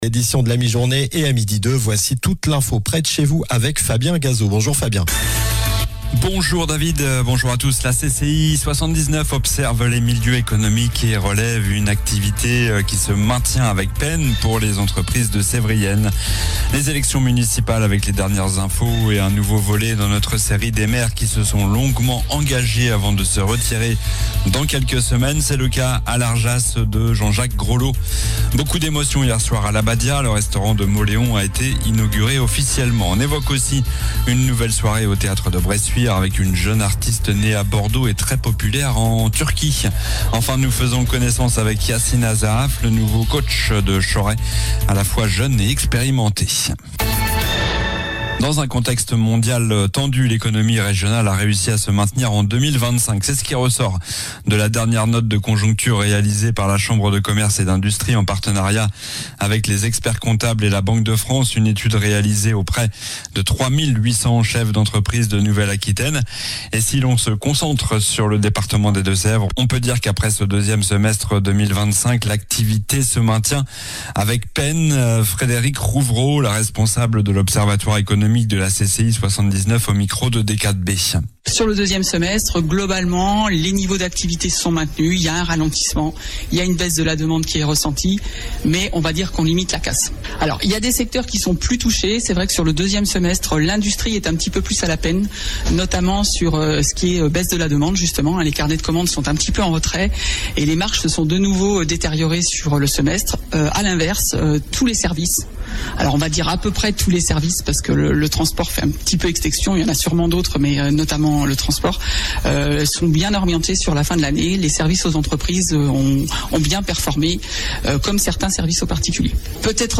Journal du jeudi 26 février (midi)